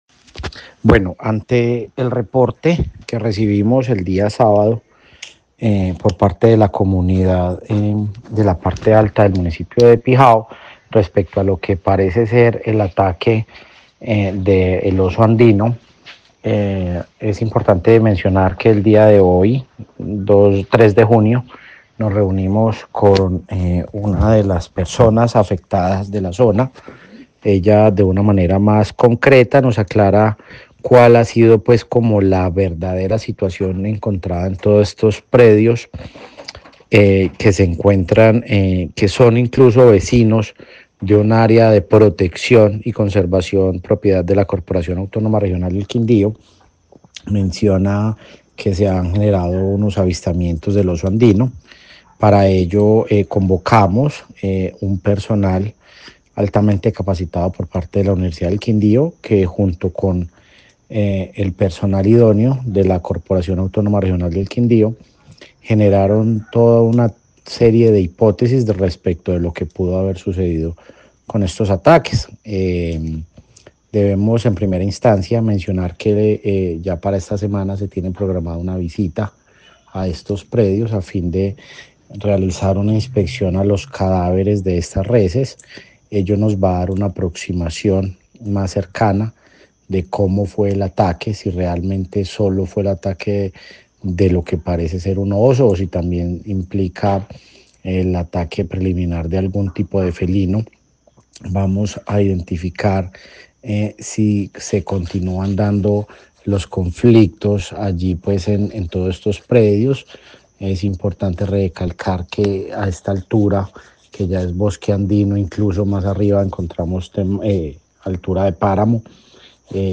Juan Esteban Cortés, director (E) CRQ
El director de la CRQ Juan Esteban Cortes en diálogo con Caracol Radio Armenia se refirió a la denuncia que hicieron ganaderos y campesinos de zona rural del municipio de Pijao donde un oso habría atacado el ganado.